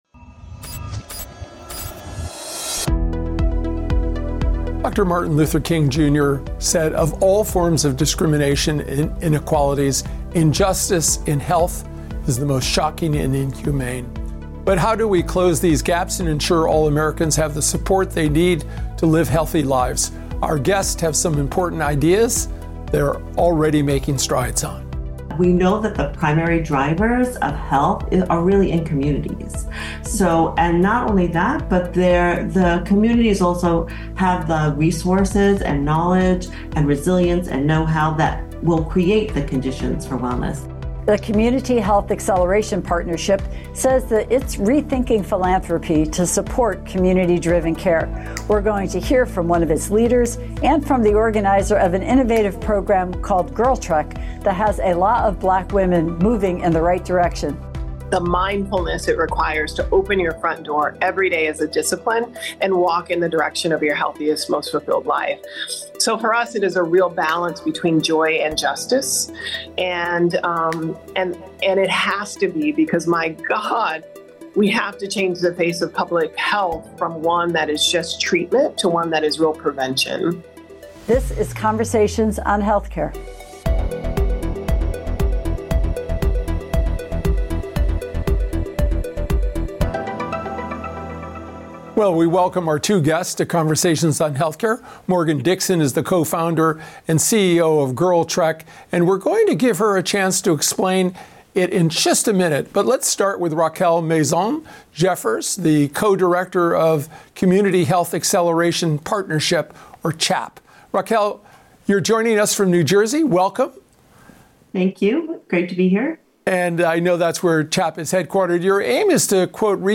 Join us for a unique conversation with an innovative nonprofit leader and the grantmaker who’s helping support her efforts.